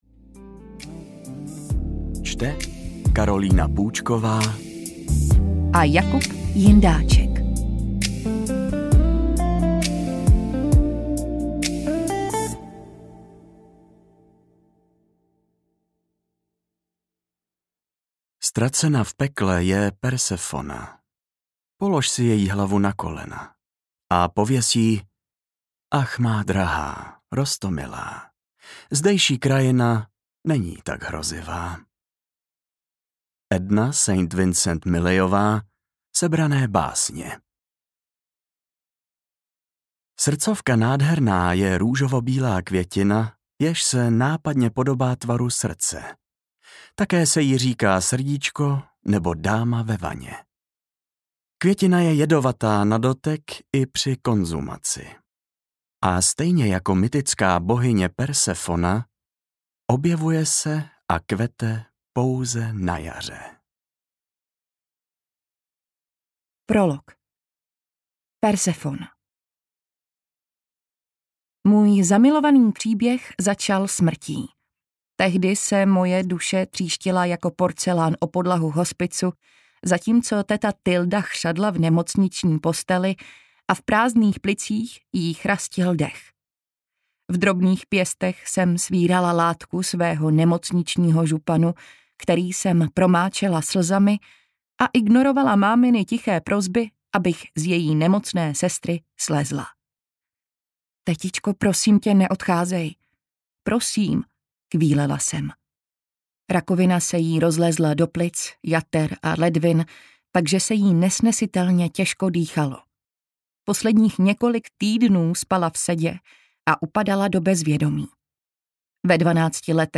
Mizera audiokniha
Ukázka z knihy